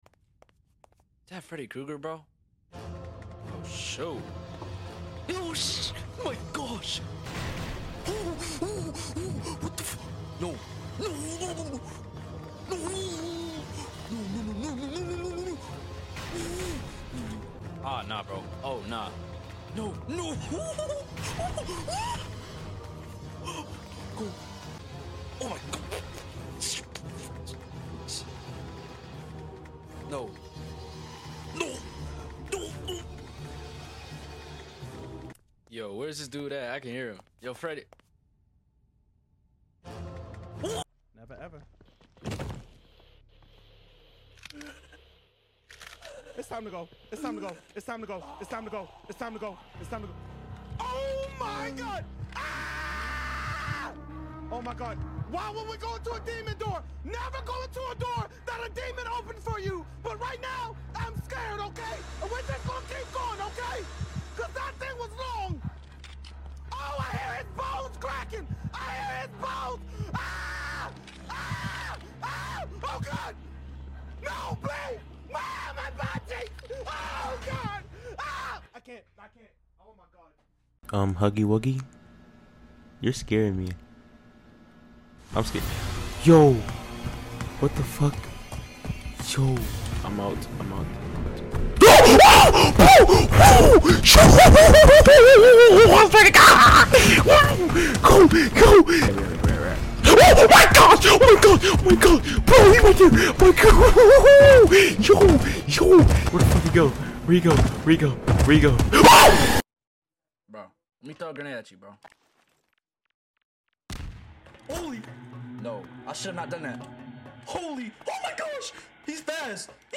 From creepy footsteps to full-on panic, these are the Top 5 ‘Run Faster Bro I’m Scared’ Moments…hilarious clips of players losing it while being chased in horror games!